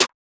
murda snare.wav